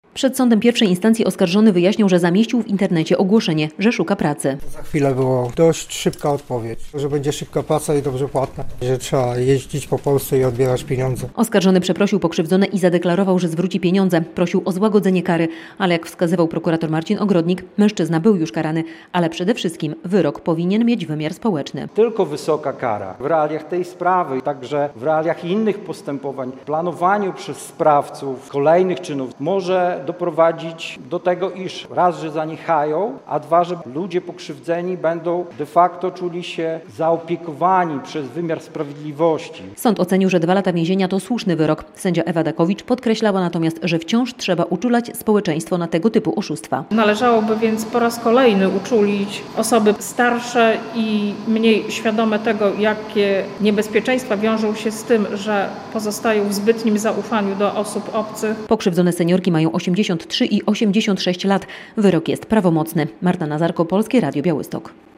Wyrok oskarżonego o udział w oszustwach metodą „na policjanta” - relacja